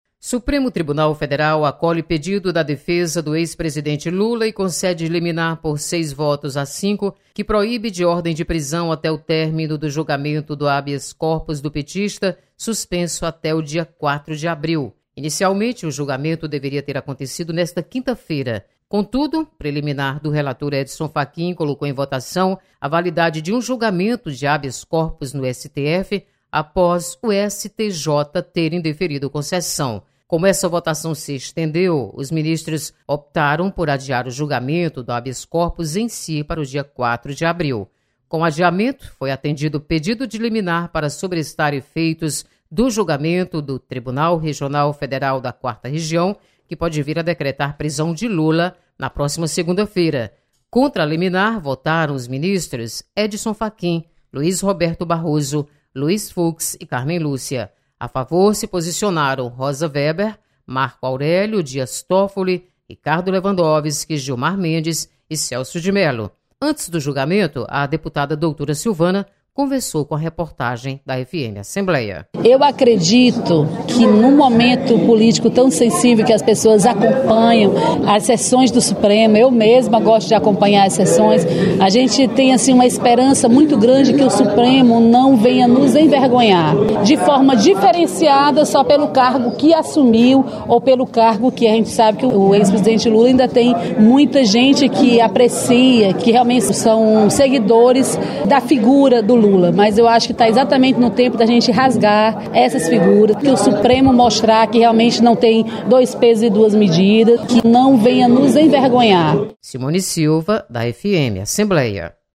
Deputada Doutora Silvana espera que o STF não envergonhe o País.